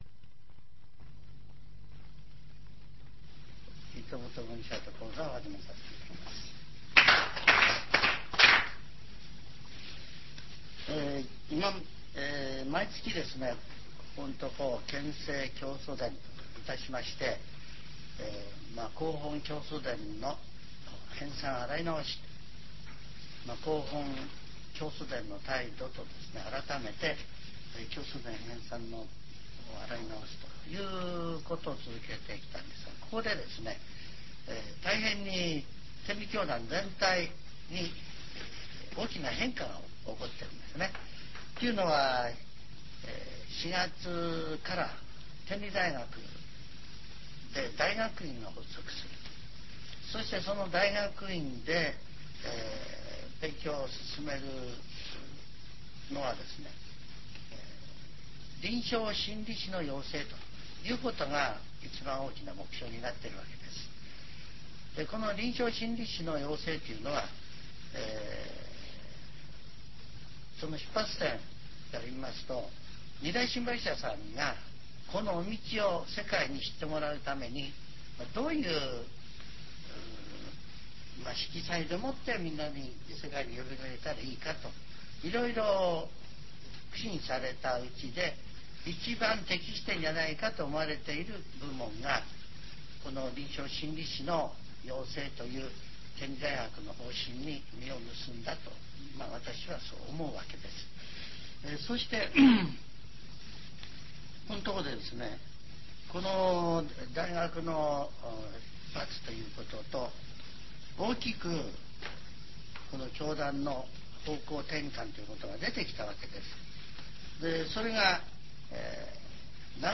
ジャンル: Speech